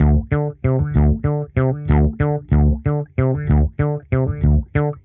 Index of /musicradar/dusty-funk-samples/Bass/95bpm